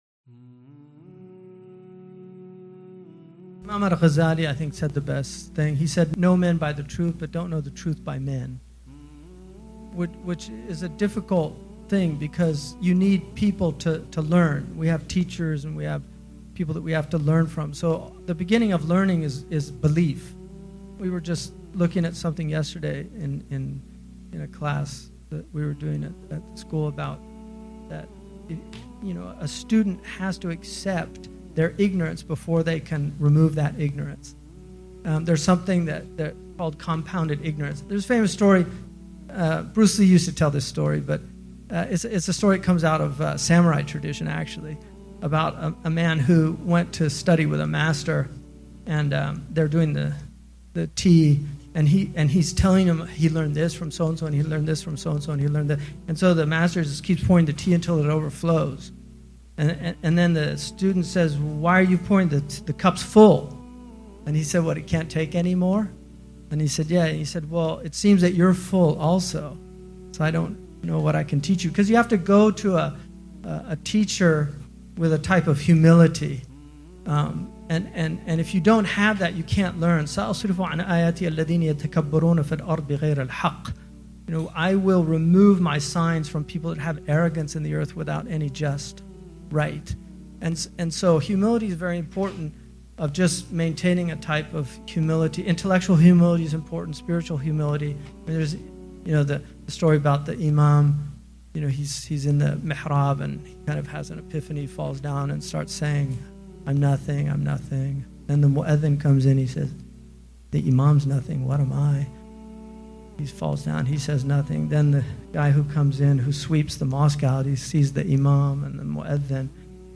A motivational Speech by Hamza Yusuf.mp3